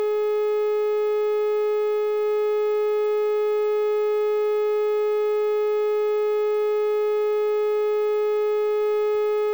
Electric, Permanent Tone, High frequency, note A4.wav